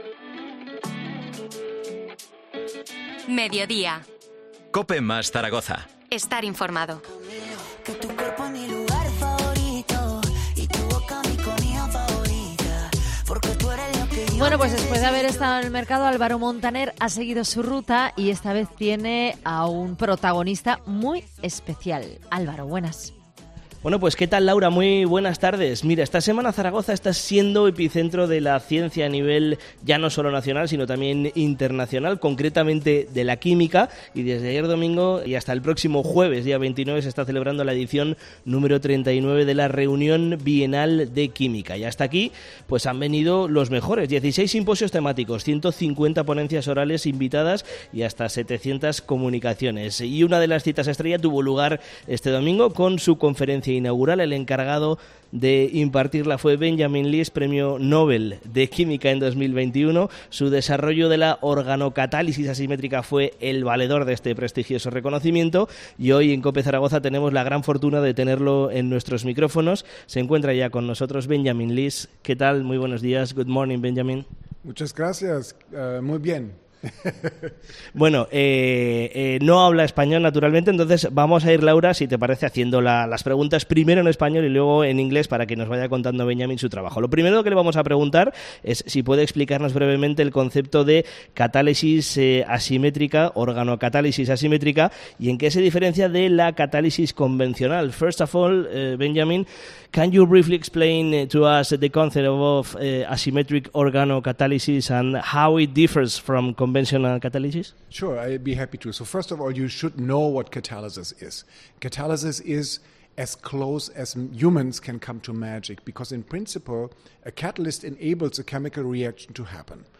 Entrevista a Benjamin List, premio Nobel de Química 2021